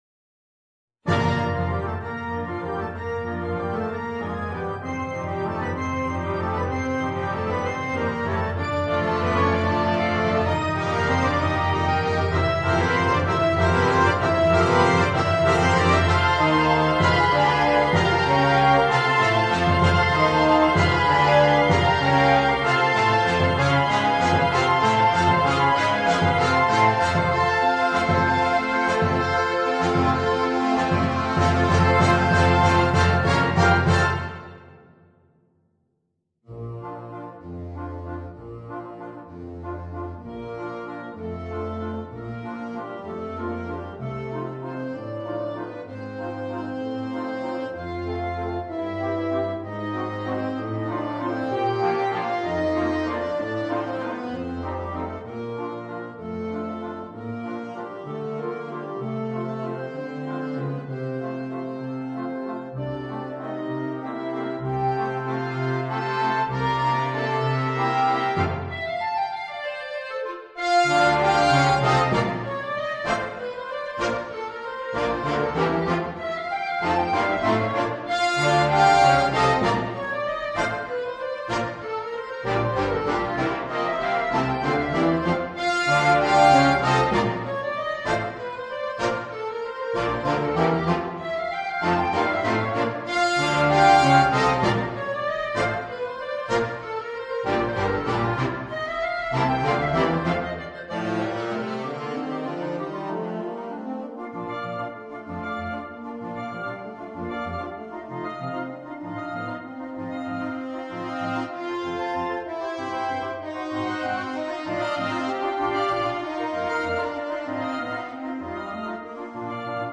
per banda